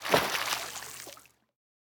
splash_medium.ogg